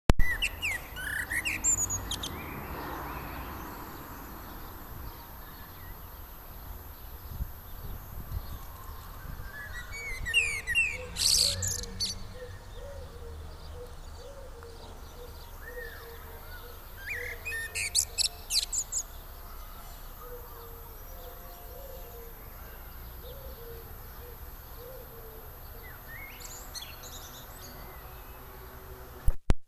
Merlo Turdus merula
merlo.wma